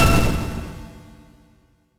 Energy Impact 1.wav